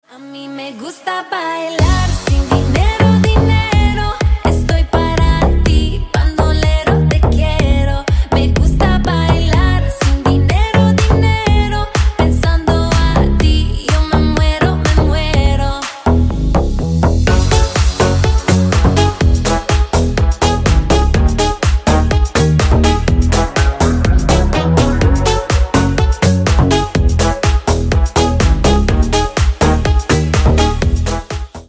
Ремикс
латинские # клубные